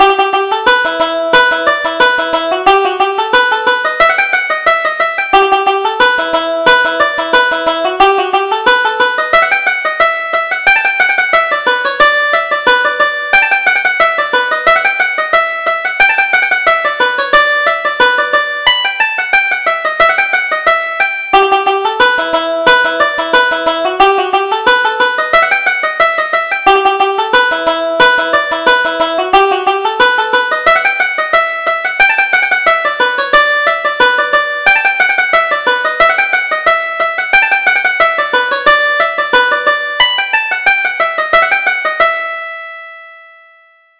Reel: Jim Kennedy's Favorite